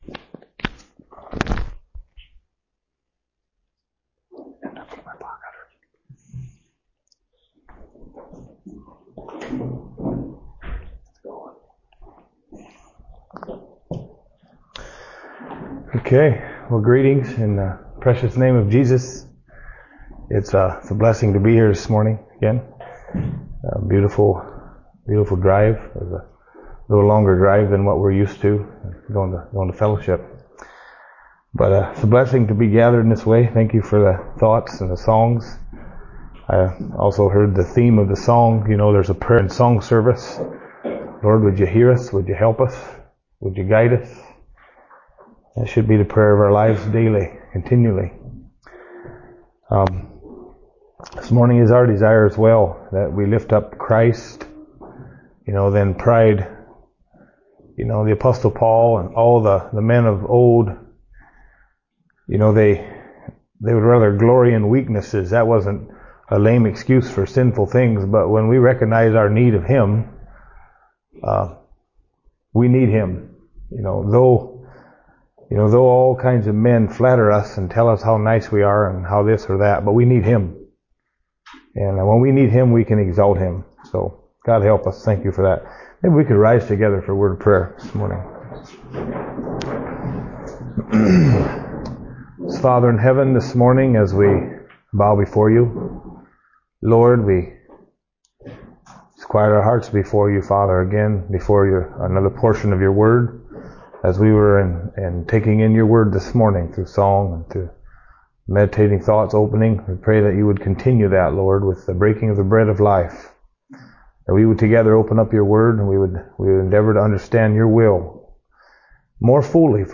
Sermons | Be Strengthened and Encouraged by God's Word